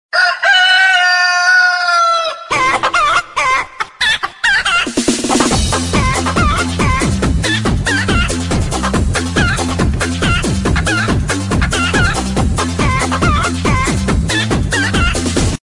Genres: Alarm (21) - Animals (30) - Funny (67) - Rooster (8)